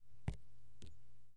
纸上滴水 " 纸上滴水 10
描述：滴在纸上。
标签： 下落
声道立体声